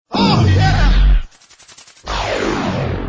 oh! yeah fx